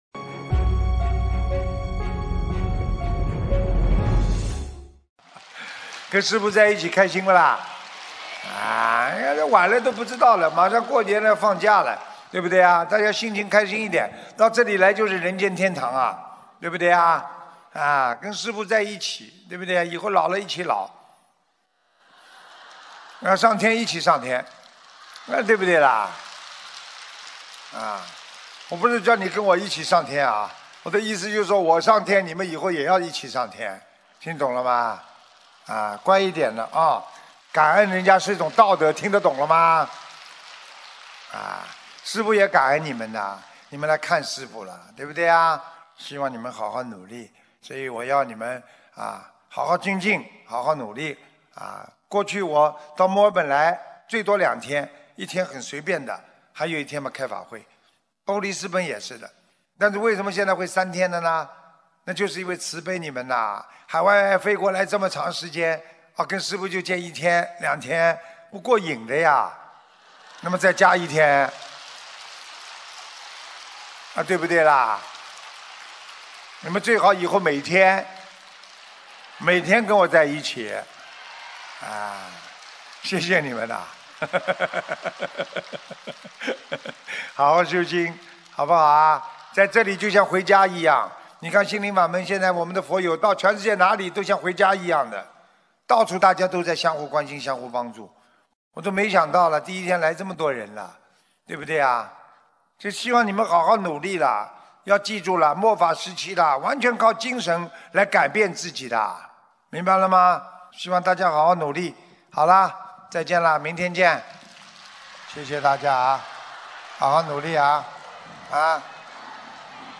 2019年12月6日墨尔本世界佛友见面会结束语-经典感人开示